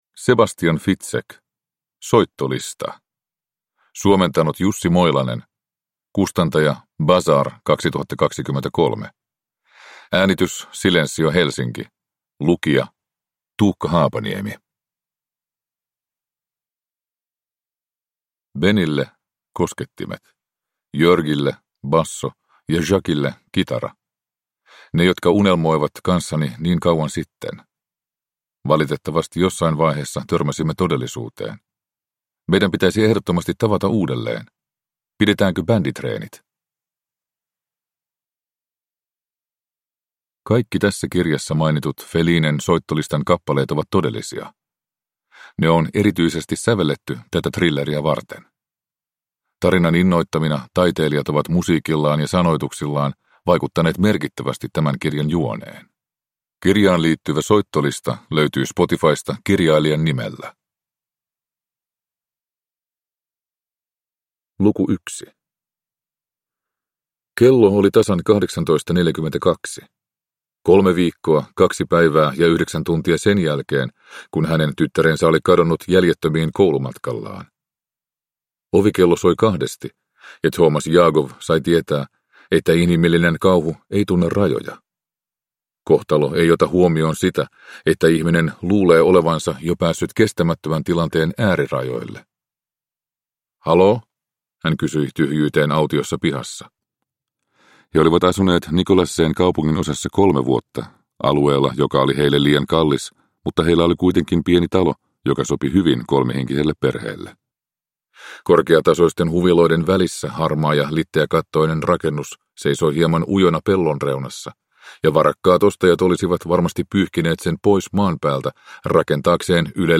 Soittolista – Ljudbok – Laddas ner